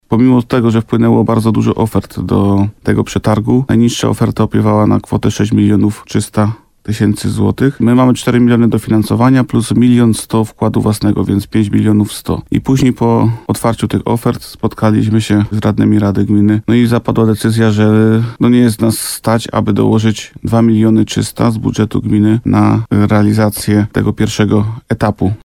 Spotkaliśmy się z radnymi i zapadła decyzja, że nie stać nas, aby dołożyć 2,3 miliona zł, na realizację tego pierwszego etapu – mówił wójt gminy Łukowica, Bogdan Łuczkowski w programie Słowo za Słowo na antenie RDN Nowy Sącz.
Rozmowa z Bogdanem Łuczkowskim: Tagi: Słowo za Słowo Limanowa stadion Bogdan Łuczkowski Łukowica Przyszowa